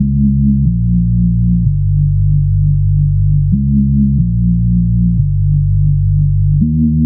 Index of /90_sSampleCDs/Club_Techno/Bass Loops
BASS_136_3-G.wav